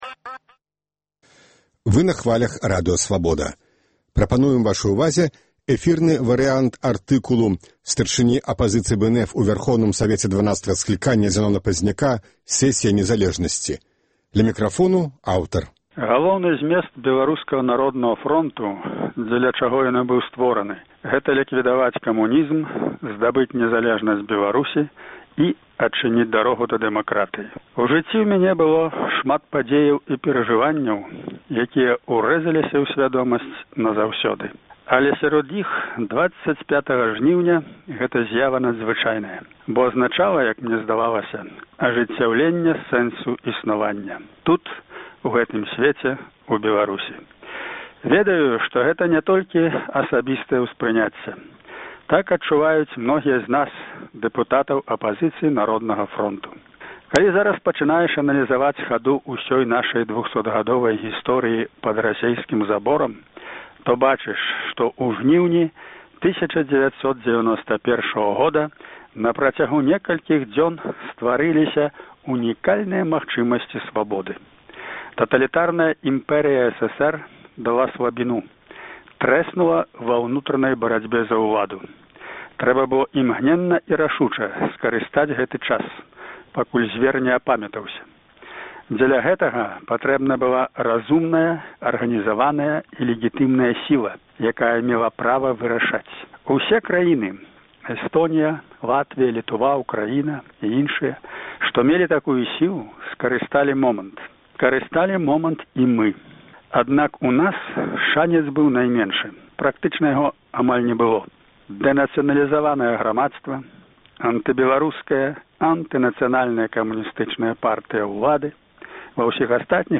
Эфірны варыянт артыкула Зянона Пазьняка пра надзвычайную сэсію Вярхоўнага Савета Беларусі 24-25 жніўня, на якой быў нададзены статус канстытуцыйнай сілы Дэклярацыі аб дзяржаўным сувэрэнітэце Беларусі.